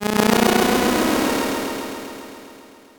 ギャグ・アニメ調（変な音）
illusion_sword.mp3